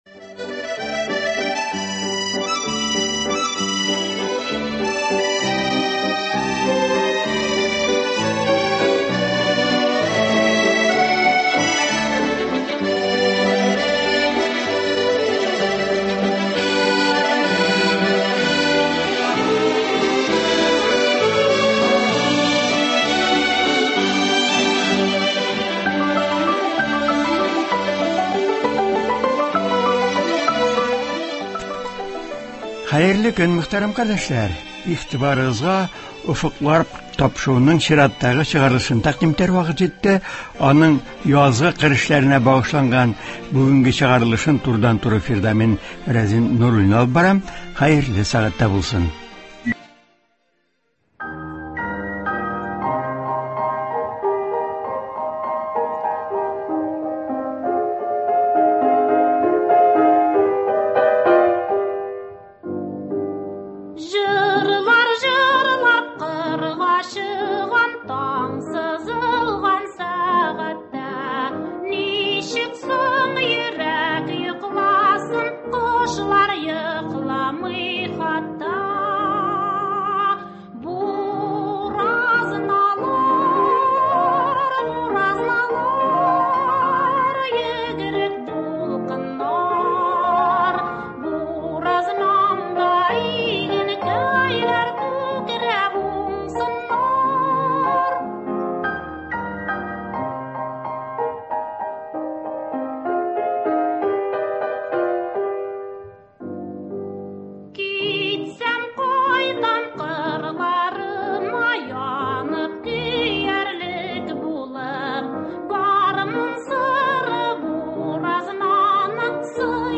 Быелгы яз соңлап килсә дә, игенчеләребез язгы кыр эшләрен алдан билгеләнгән график нигезендә алып баралар. Язгы чәчүнең барышы, мул уңыш үстерү өчен башкарыла торган башка эшләр турында турыдан-туры эфирда
алдынгы хуҗалыкларны атый, тыңлаучылар сорауларына җавап бирә.